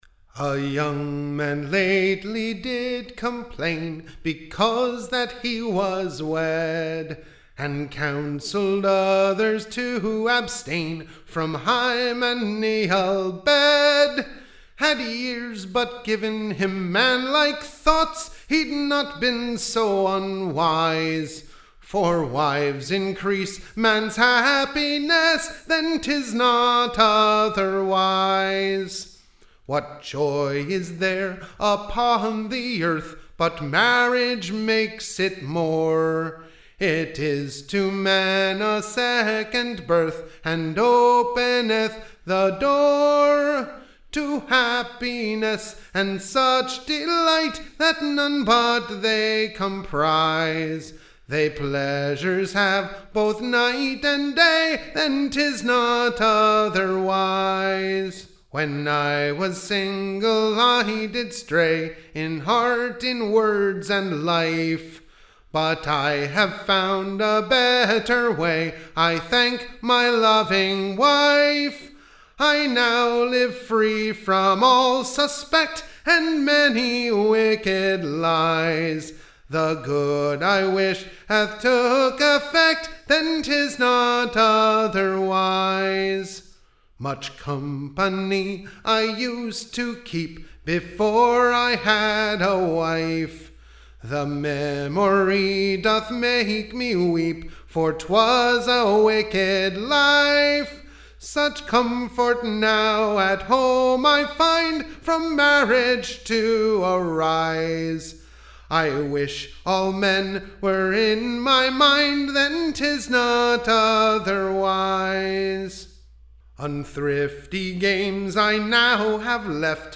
EBBA 20183 - UCSB English Broadside Ballad Archive